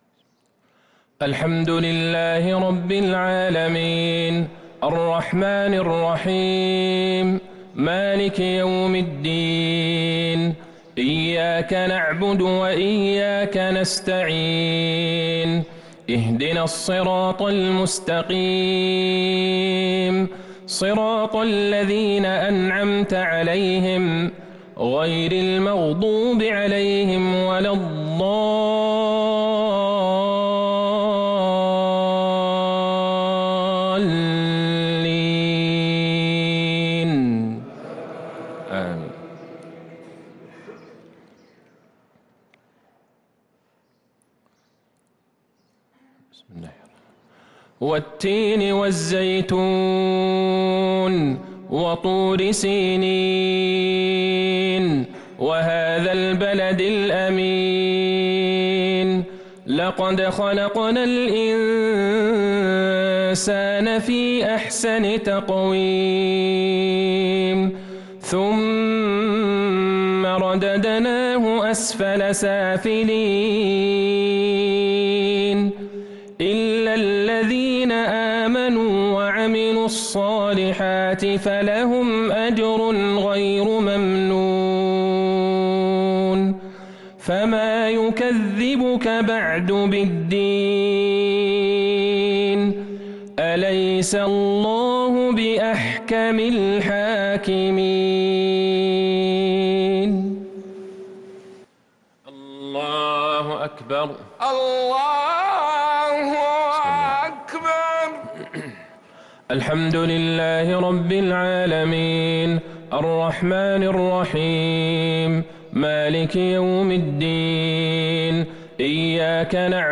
مغرب السبت ١ رمضان ١٤٤٣هـ | سورتي التين و القدر | Maghrib prayer from Surah At-Tain & Al-Qadr 2-4-2022 > 1443 🕌 > الفروض - تلاوات الحرمين